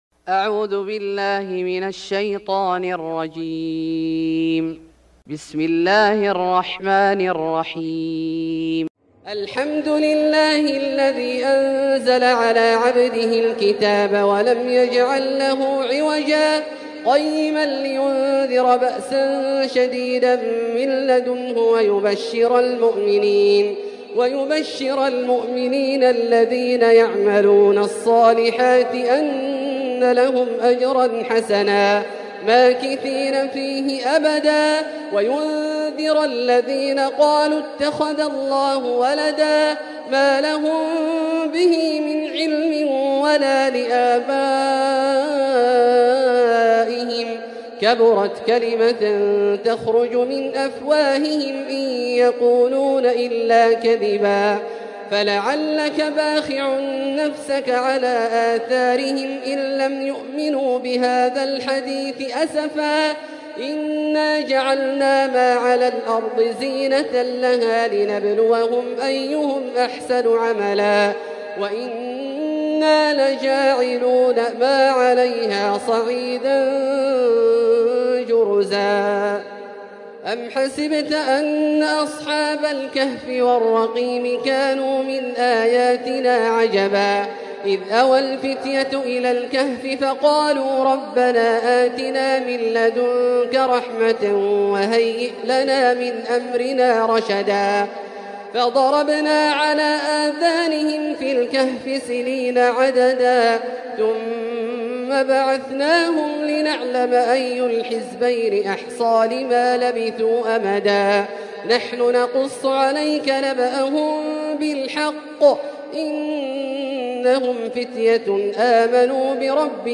سورة الكهف Surat Al-Kahf > مصحف الشيخ عبدالله الجهني من الحرم المكي > المصحف - تلاوات الحرمين